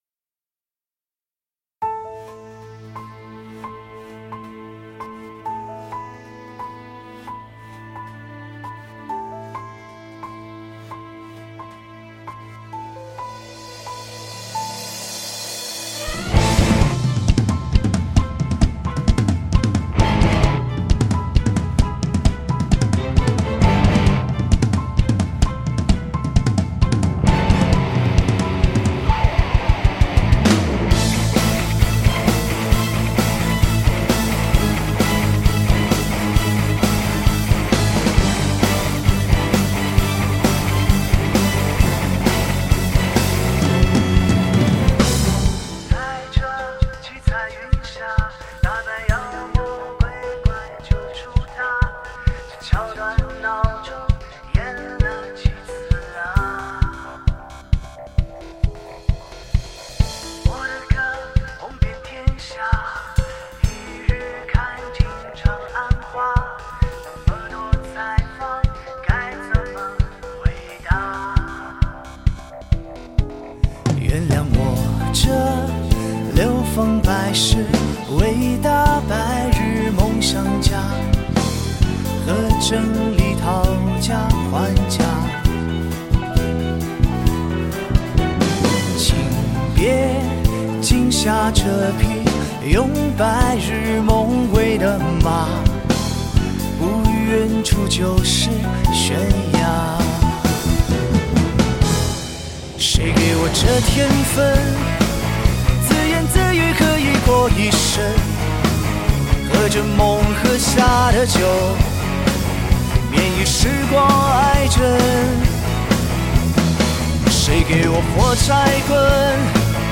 吉他手
贝斯手
鼓手
键盘
打击乐